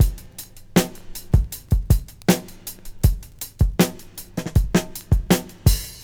• 106 Bpm HQ Drum Groove F# Key.wav
Free breakbeat sample - kick tuned to the F# note. Loudest frequency: 1647Hz
106-bpm-hq-drum-groove-f-sharp-key-bSG.wav